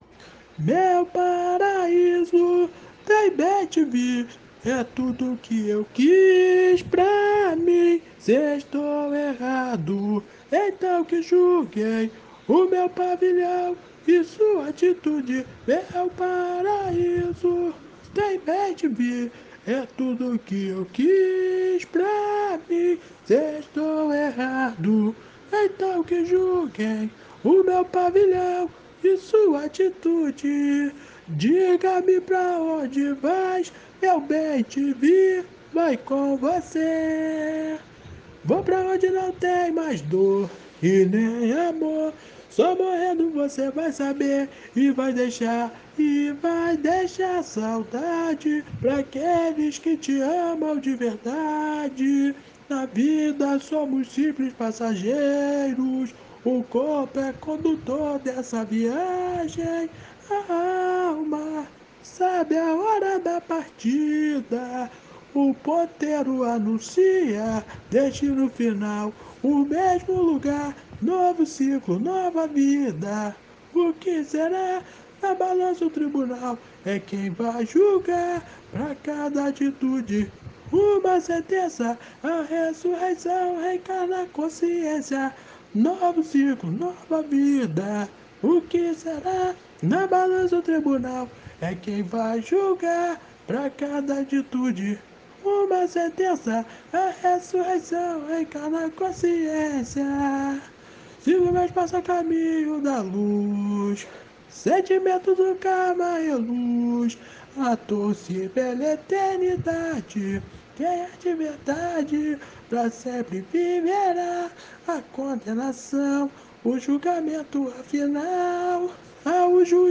Samba  04